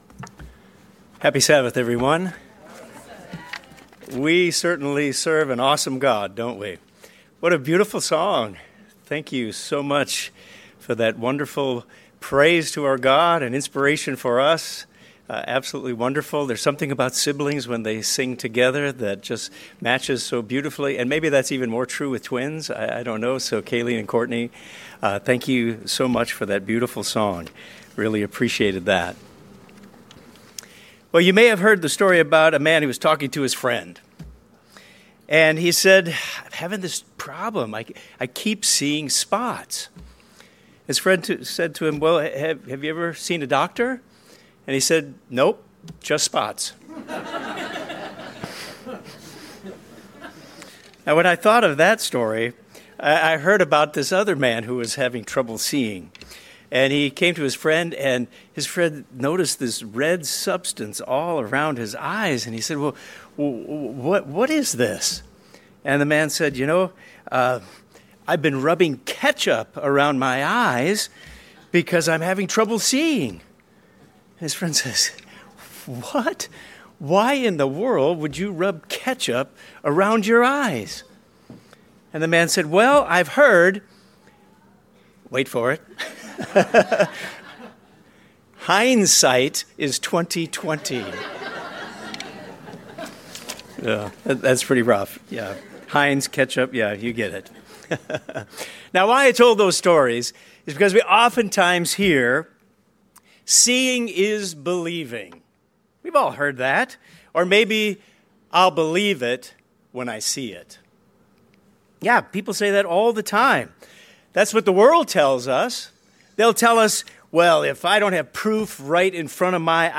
This sermon frames God's perspective for us when we face uncertainty, silence, and the long wait for answers.